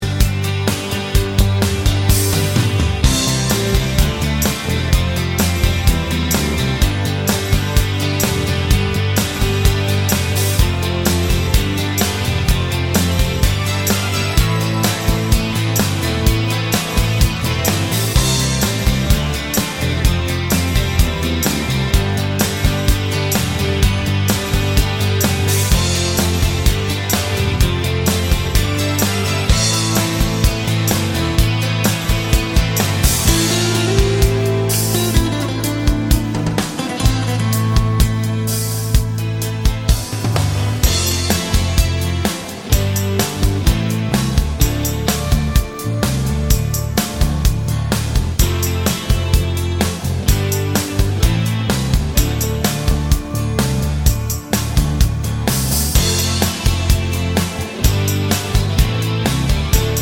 no Backing Vocals Soft Rock 4:25 Buy £1.50